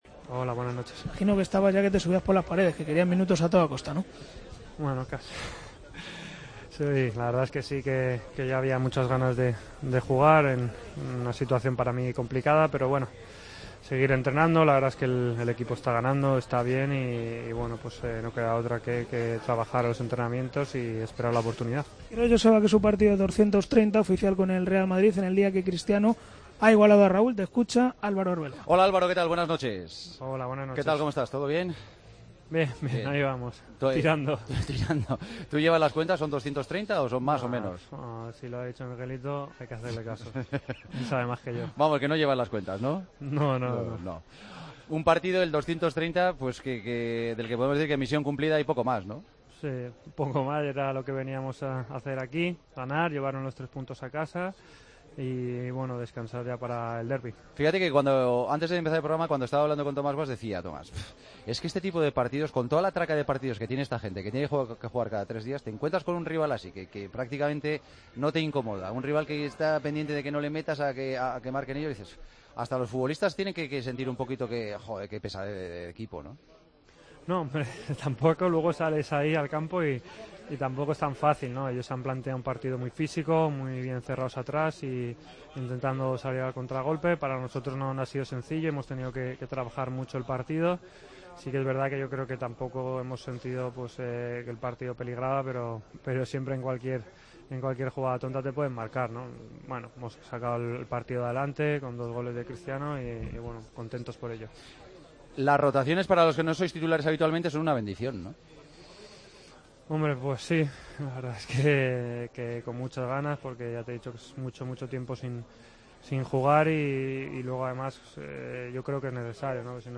Entrevista a Arbeloa, en El Partido de las 12: "Ya había muchas ganas de jugar, era una situación complicada para mí"